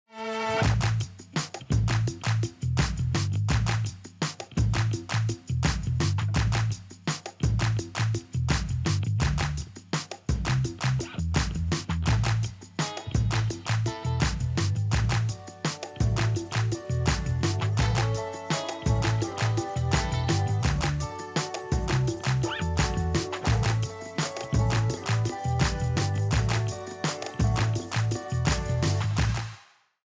MUSIC TRACK (for reference only)